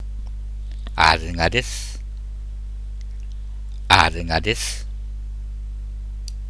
秋田の道端で交わされている挨拶です